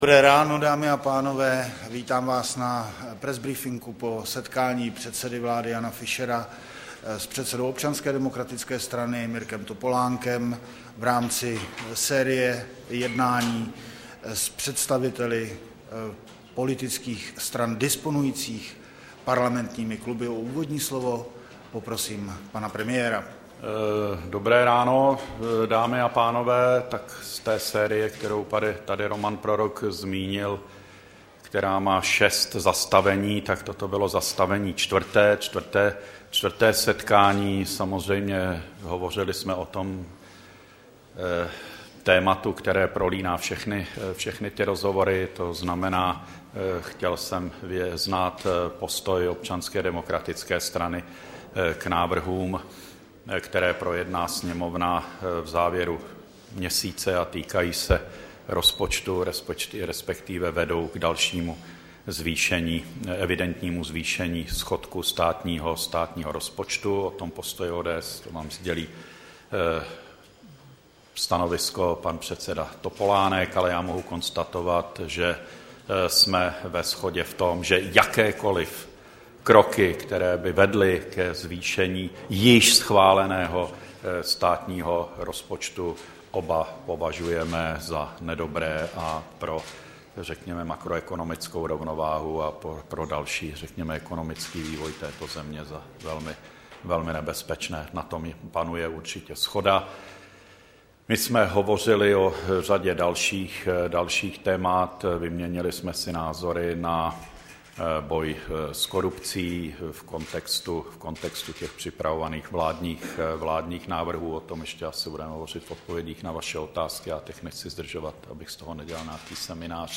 Tiskový brífink po jednání s Mirkem Topolánkem, 14. ledna 2010